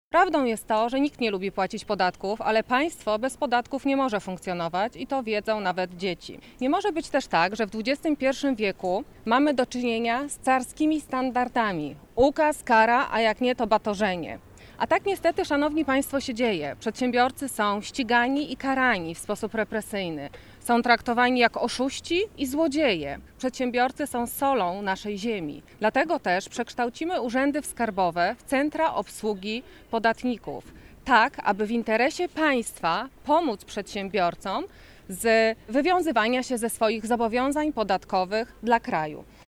Platforma Obywatelska chce, by Urzędy Skarbowe stały się Centrami Obsługi Podatnika, w których przedsiębiorcy uzyskają niezbędną pomoc, mówi Anna Sobolak – Radna PO Rady Powiatu Wrocławskiego.